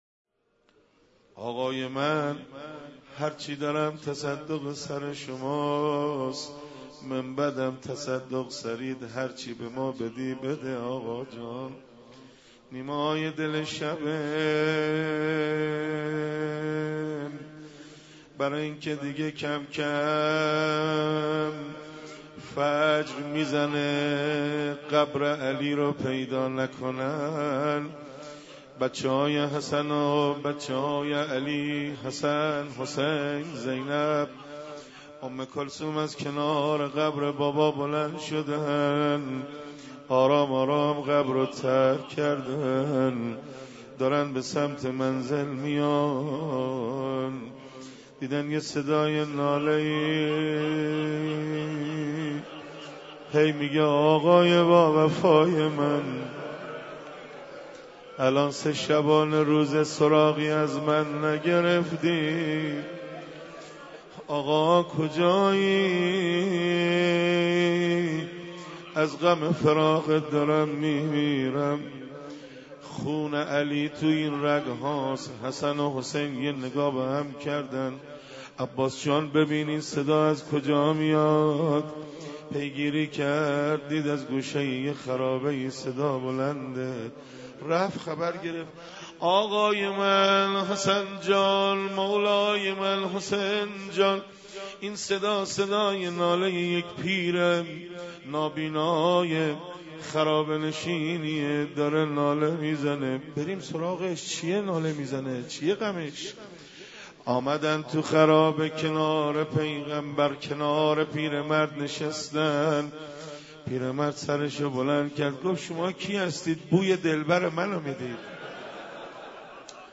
مراسم شب بیست و سوم ماه مبارک رمضان با مداحی حاج حسن خلج در مسجد حضرت امیر(ع) برگزار گردید